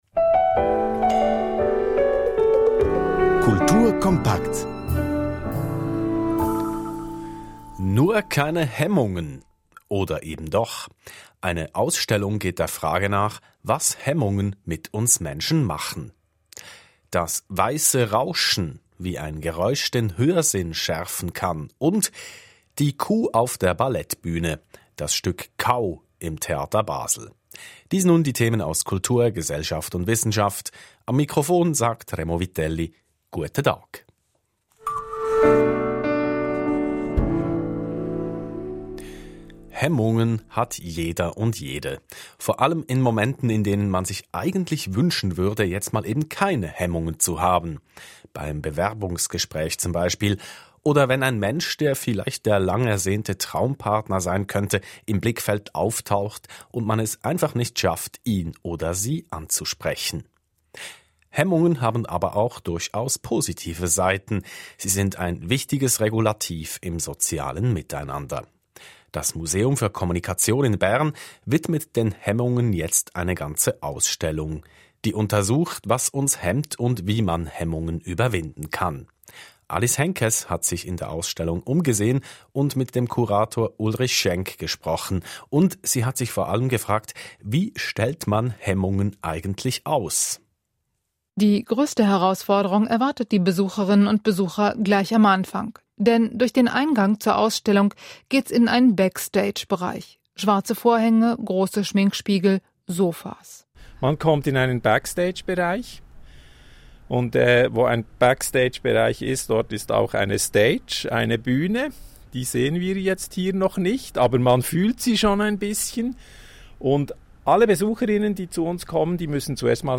It has also been noticed by the swiss radio, who called me for an interview on the fly – in german! it was transmitted on RTS2 and in a podcast of Kultur kompakt: